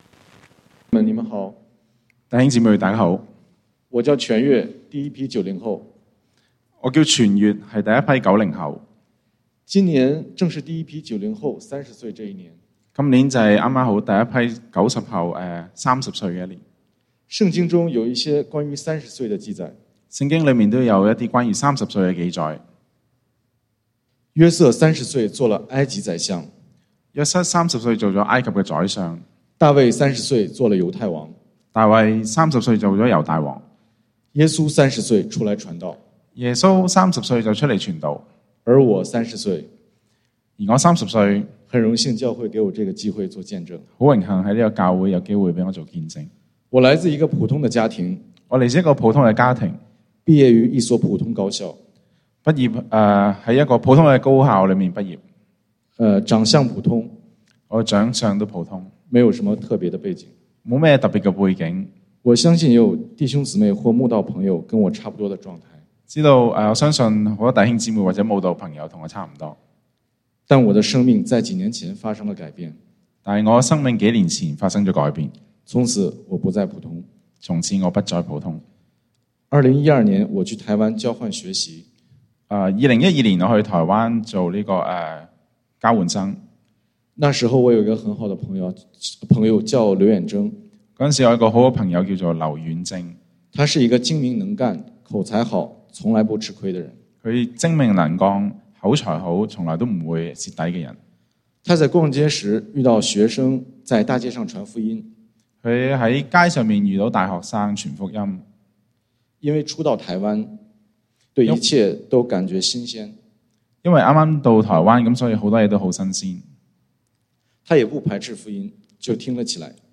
福音主日